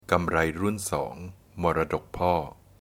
กำไลรุ่นสอง “มรดกพ่อ” (gam-lai rûn sŏng “mor-rá-dòk pôr”)
/ Pronounced in Thai.